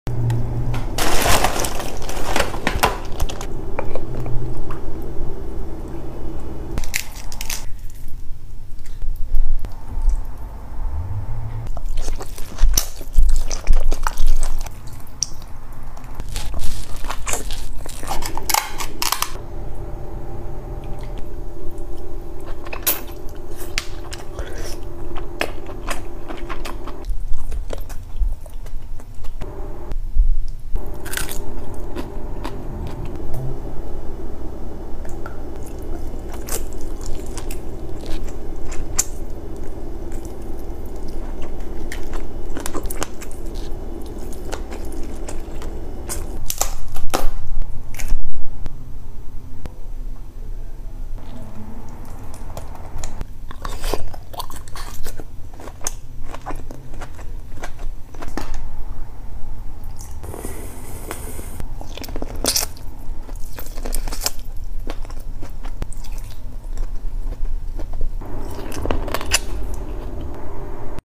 Ngl, messiest Mp3 Sound Effect $300 seafood boil.. Ngl, messiest mukbang ive ever done !